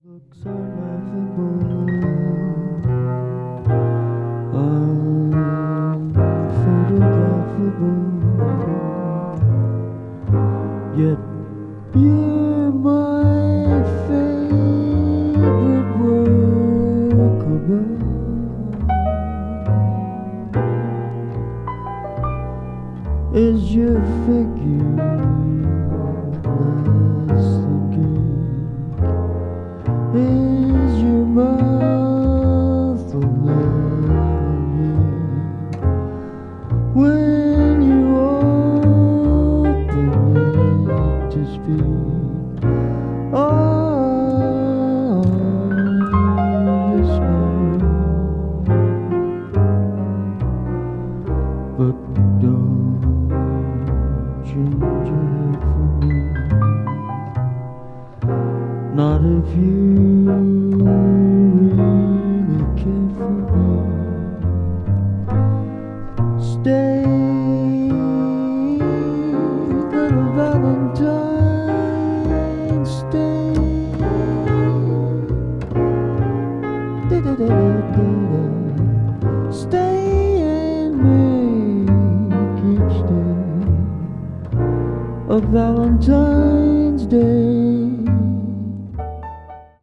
柔らかでシンプルなトランペットの旋律
contemporary jazz   jazz standard   modal jazz   modern jazz